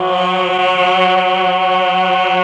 RED.CHOR1 12.wav